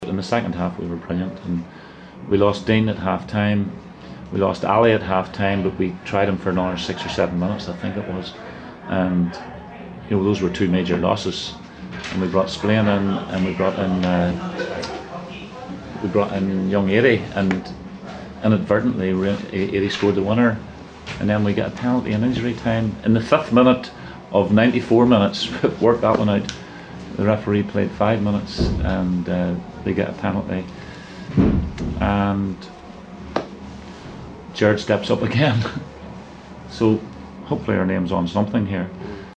Upbeat Kenny Shiels gives his reaction after great cup win
Derry City boss Kenny Shiels was understandably upbeat after his side beat St. Patrick’s Athletic 1-0 to book their place in the FAI Cup quarter-finals.